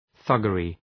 Προφορά
{‘ɵʌgərı}